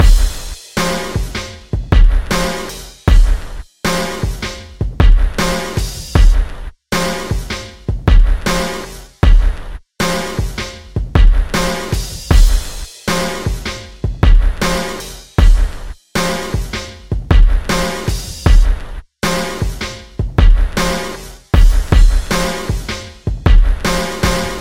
标签： 78 bpm Glitch Loops Drum Loops 4.14 MB wav Key : Unknown
声道立体声